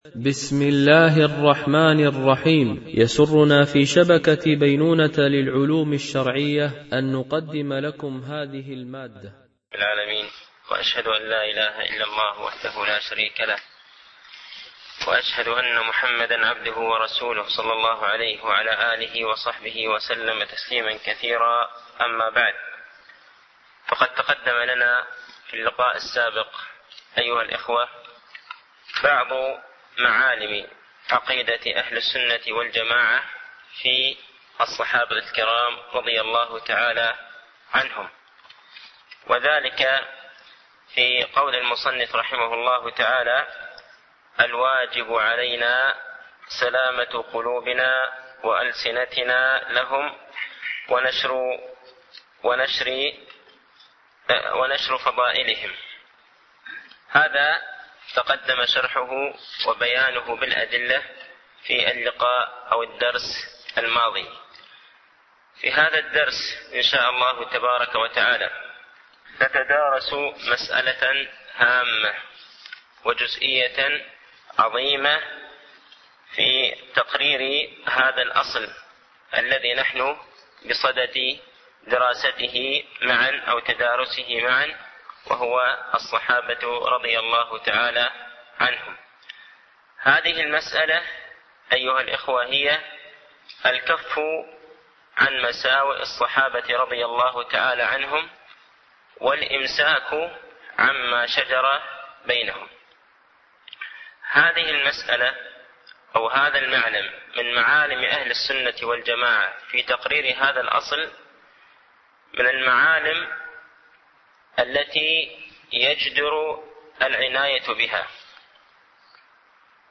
) الألبوم: شبكة بينونة للعلوم الشرعية التتبع: 135 المدة: 53:41 دقائق (12.32 م.بايت) التنسيق: MP3 Mono 22kHz 32Kbps (CBR)